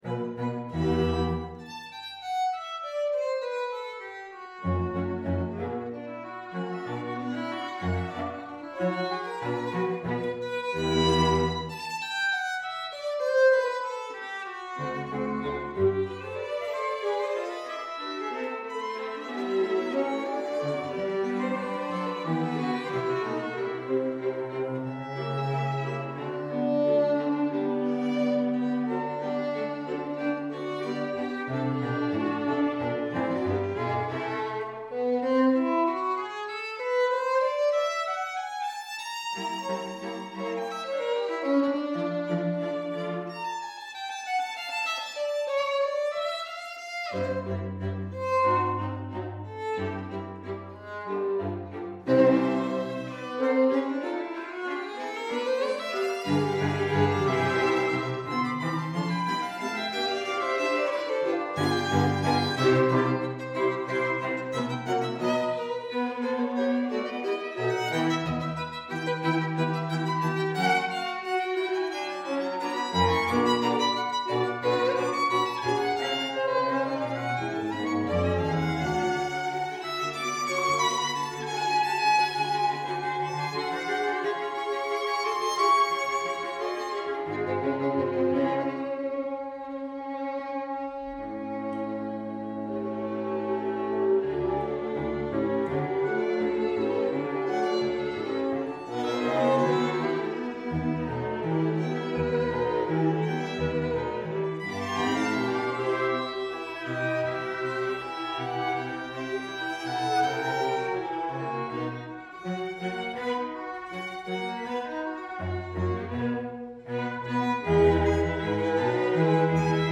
For 2 Violins, 2 Violas and Violoncello